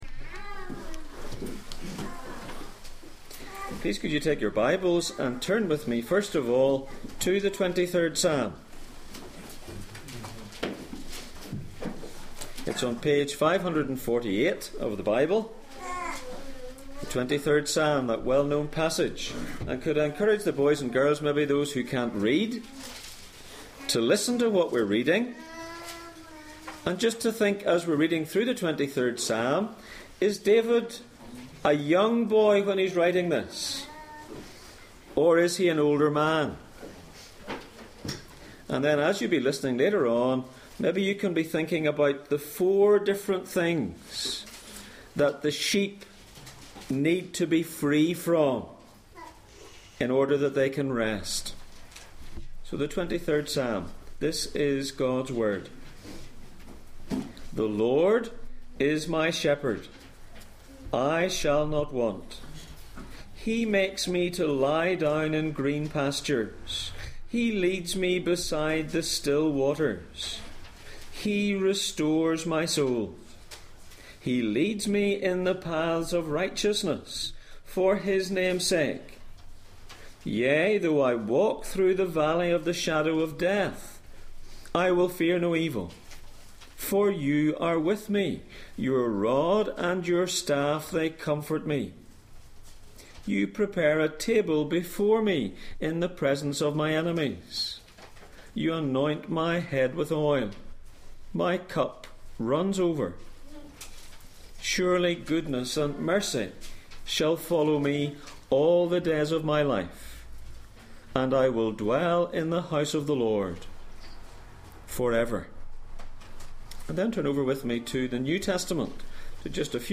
Passage: Psalm 23:2, John 10:9-15 Service Type: Sunday Morning